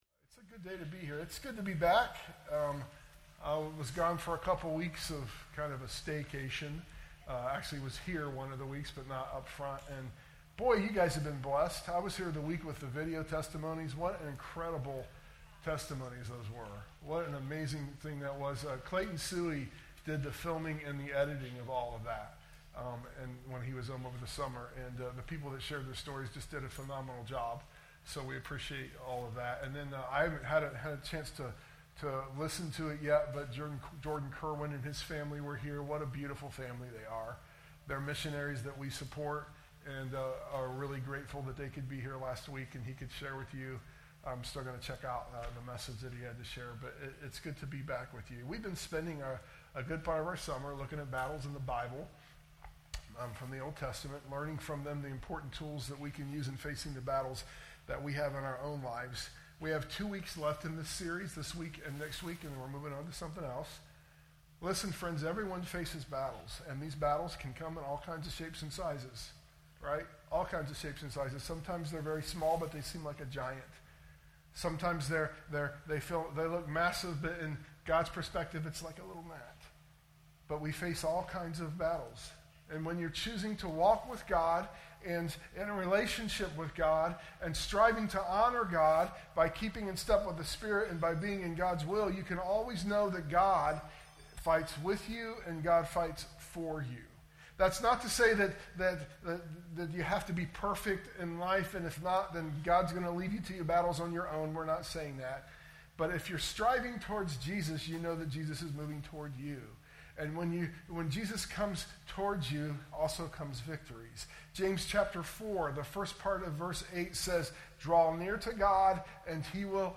sermon_audio_mixdown_8_24_25.mp3